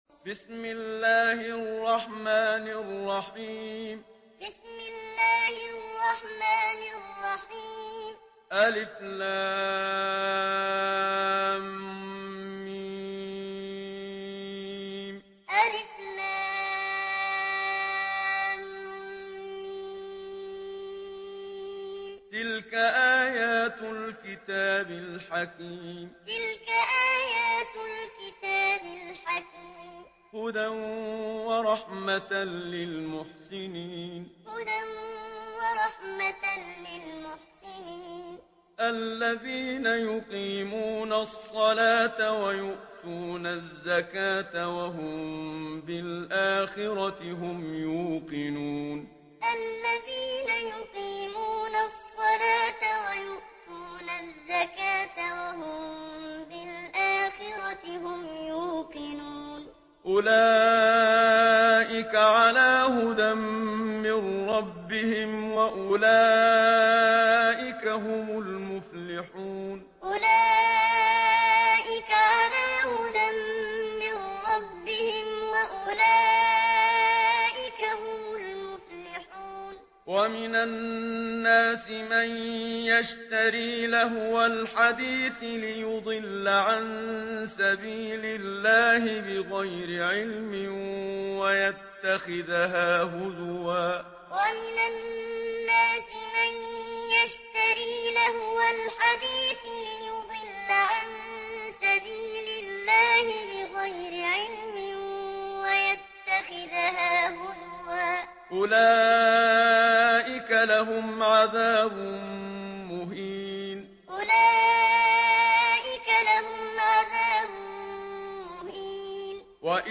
Surah Lokman MP3 in the Voice of Muhammad Siddiq Minshawi Muallim in Hafs Narration
Listen and download the full recitation in MP3 format via direct and fast links in multiple qualities to your mobile phone.